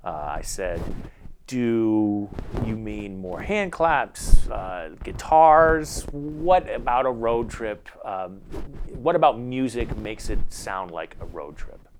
Here are some examples of lav-mic recordings where the person moved just a bit too much:
de-rustle-3.wav